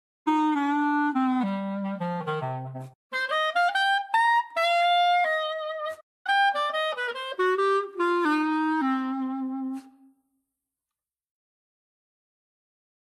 Jazz_Clarinet.mp3